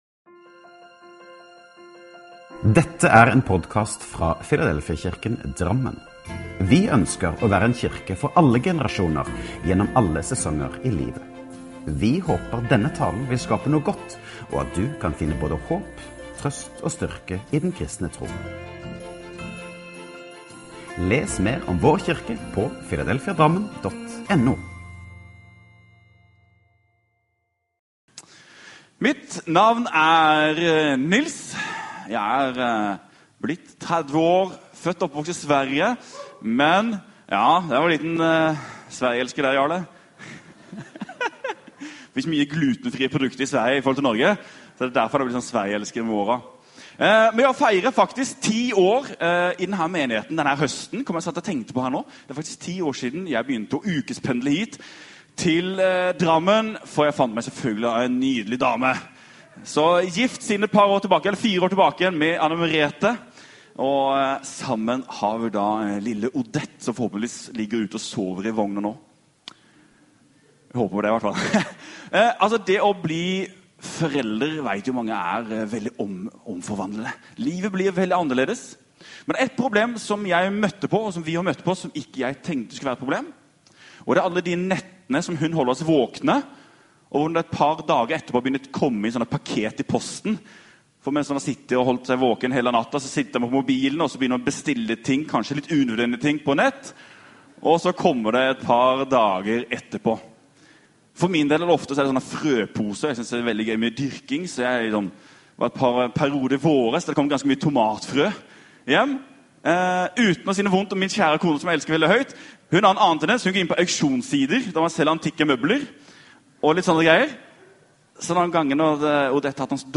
Last ned talen til egen maskin eller spill den av direkte: